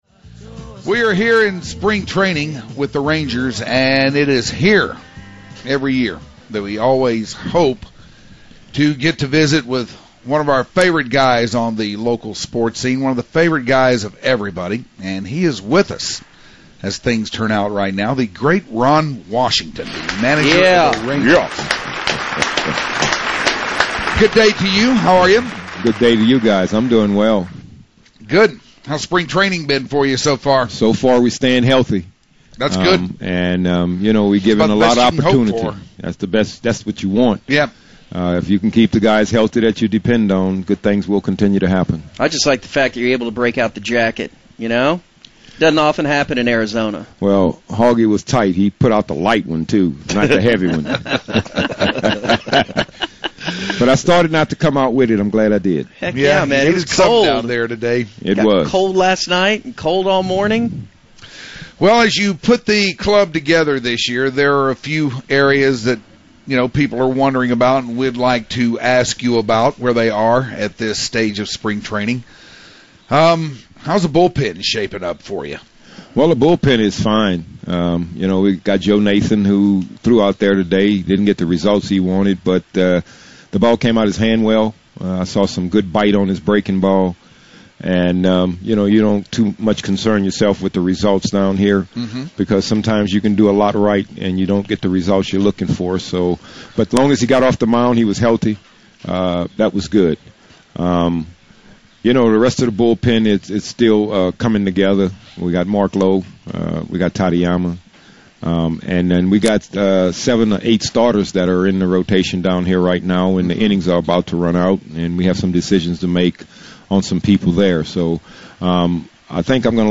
The Hardline Interviews Ron Washington (Part 1) - The UnTicket
The greatness of Ron Washington stopped by the The Hardline’s broadcast area yesterday to talk baseball and old school music.